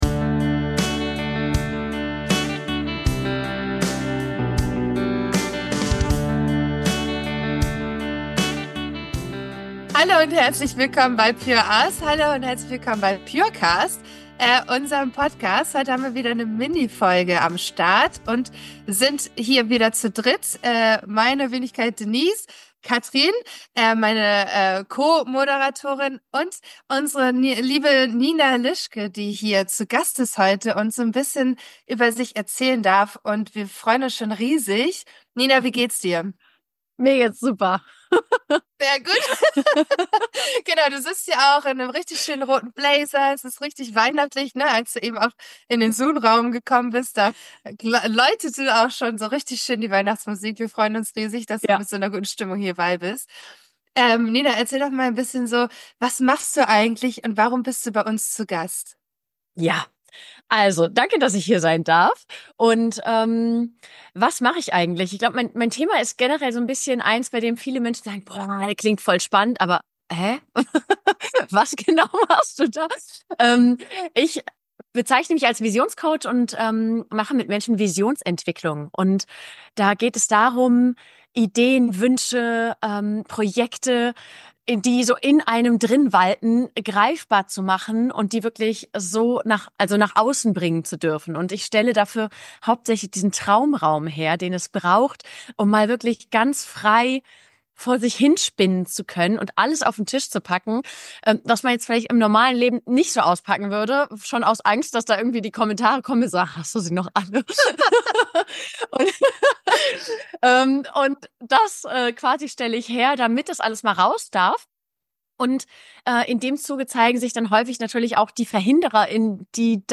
Willkommen zu einer inspirierenden Mini-Folge des Purecast!